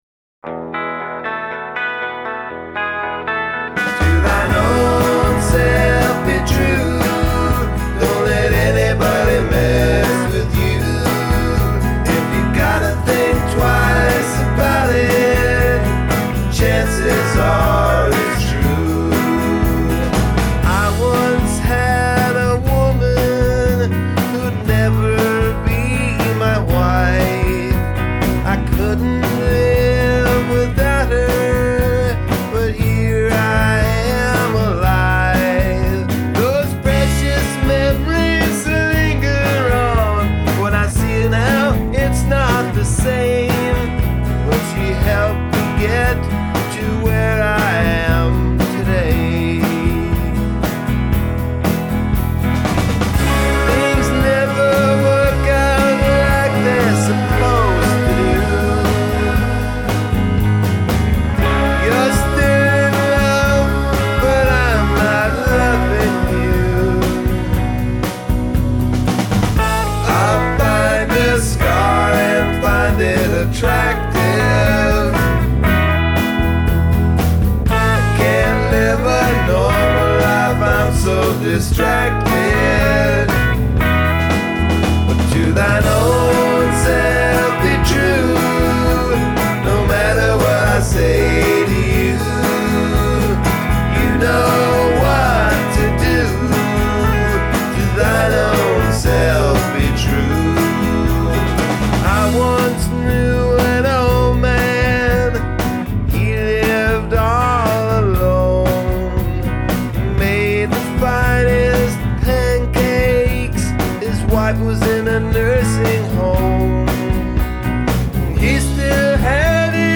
Lead Vocals and Guitar
Bass Guitar and Vocals
Drums
Vocals and Guitar